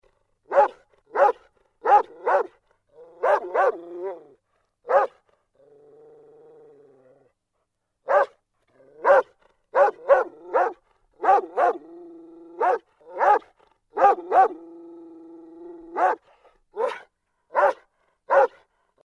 Звуки собак
Лай немецкой овчарки